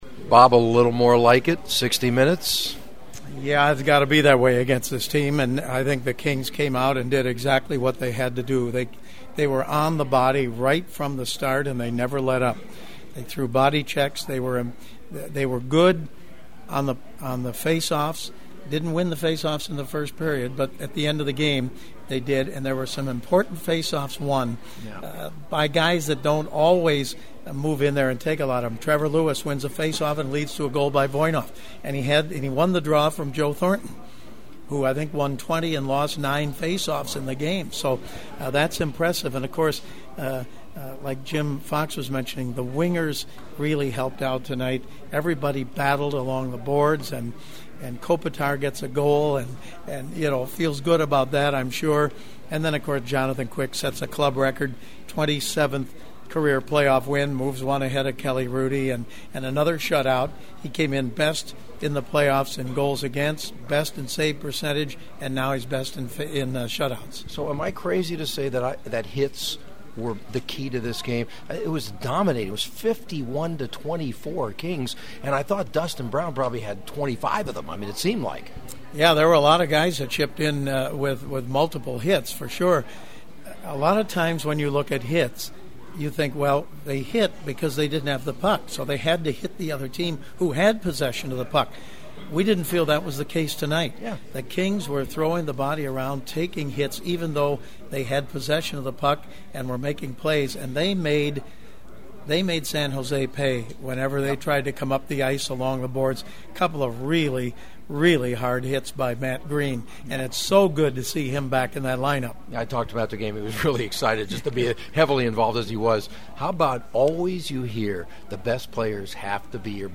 The sounds of the postgame are ahead from a confident Kings locker room:
And my very special postgame guest Kings announcer Bob Miller who’s always so gracious with his time: